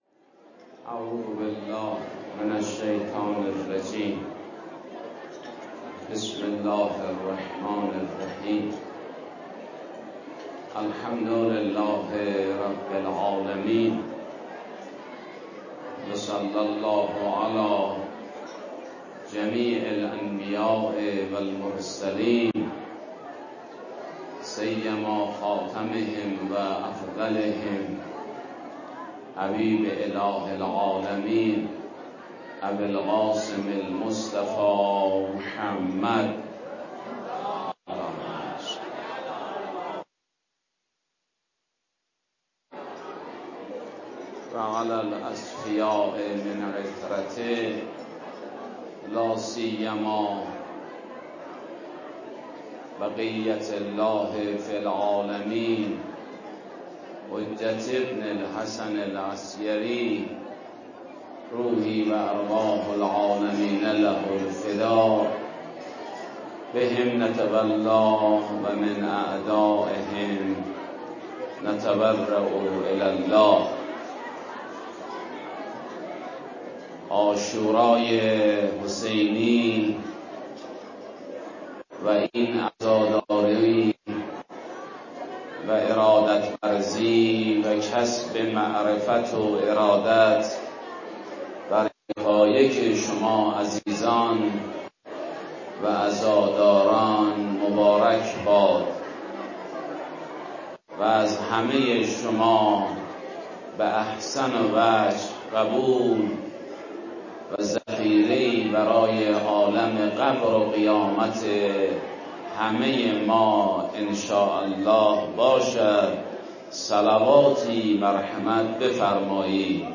روز عاشورا: مکتب عاشورا الهام‌بخش همه انسان‌هاست / مکتب امام حسین(ع)، یک مکتب زنده، پویا، جامع و الهام‌بخش است